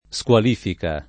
SkUal&fika] s. f. — voce del ’900, tanto nel sign. specifico di «sanzione sportiva» quanto nel generico «discredito» — raro squalificazione [SkUalifikaZZL1ne], voce ugualm. moderna, usabile al più per «l’azione di squalificare» — cfr. notifica